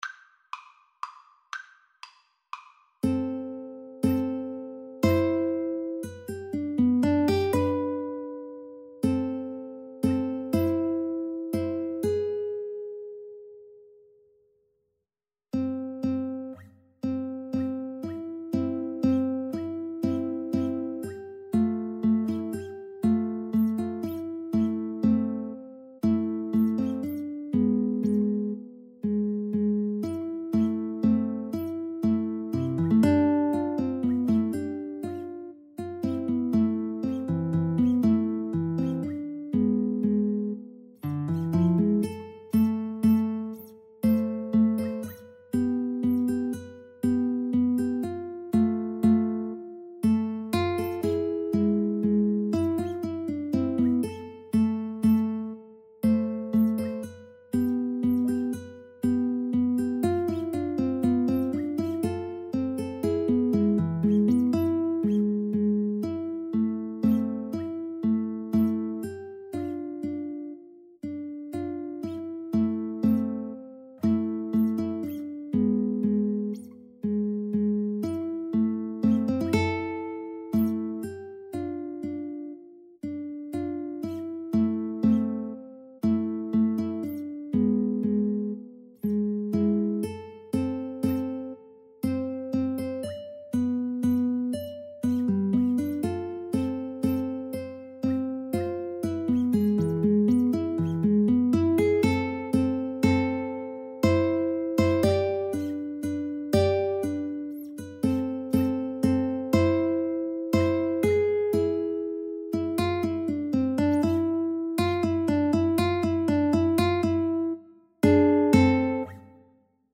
3/4 (View more 3/4 Music)
A minor (Sounding Pitch) (View more A minor Music for Guitar Trio )
Slow Waltz .=40
Traditional (View more Traditional Guitar Trio Music)